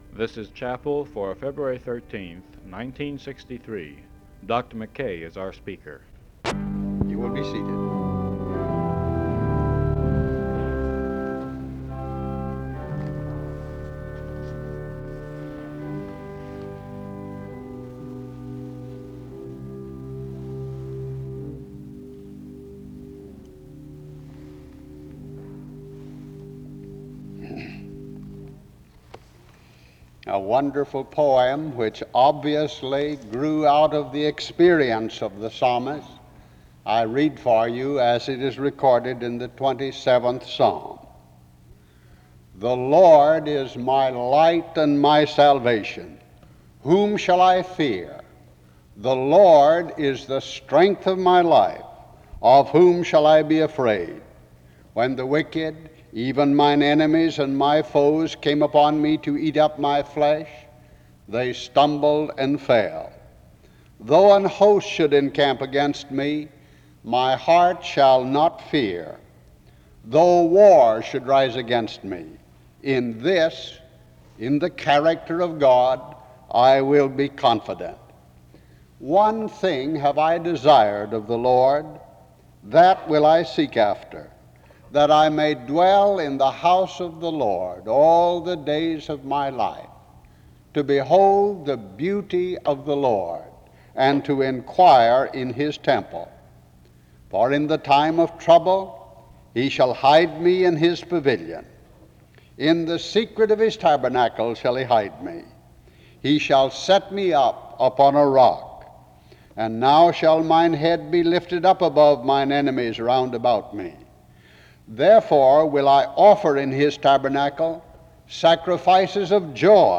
The service begins with the reading of Psalm 27 from 0:30-3:30. There is a prayer from 3:31-7:41. A musical interlude takes place from 7:42-8:10.
Music plays form 24:46-25:01.